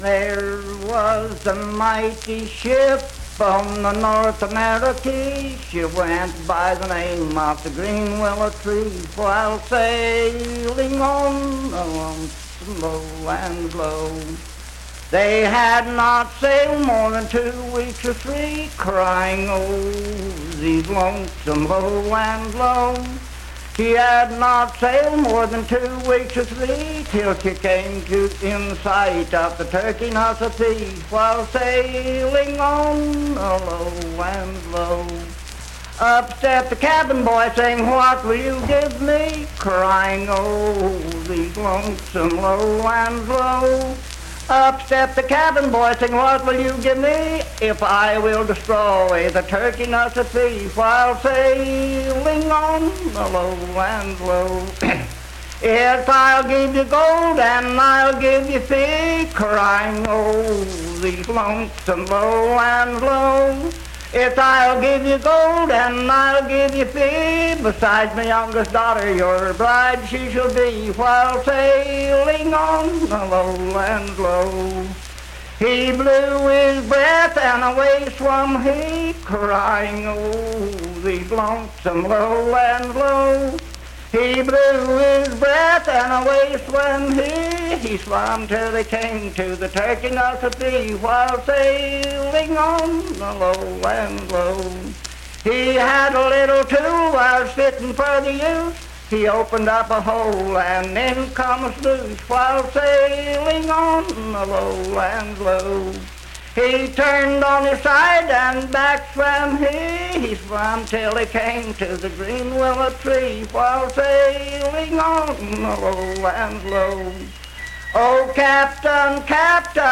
Unaccompanied vocal music performance
Verse-refrain 10 (5w/R).
Voice (sung)